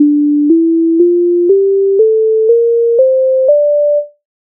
гама ре мінор
MIDI файл завантажено в тональності d-moll
Standartni_poslidovnosti_hama_re_minor.mp3